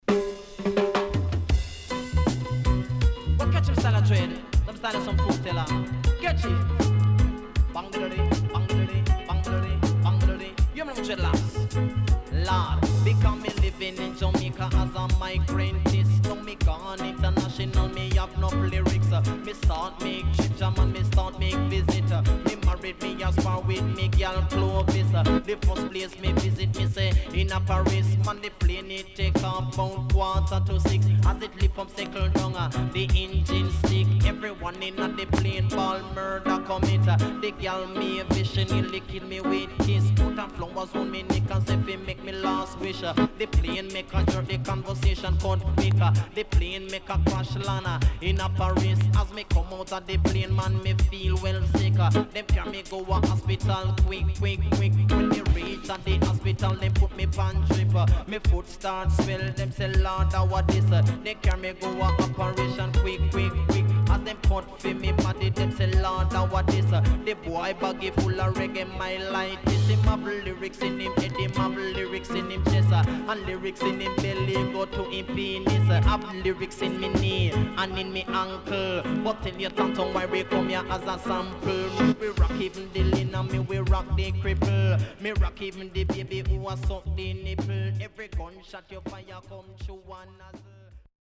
CONDITION SIDE A:VG+〜EX-
Killer Digital.W-Side Good
SIDE A:少しチリノイズ入りますが良好です。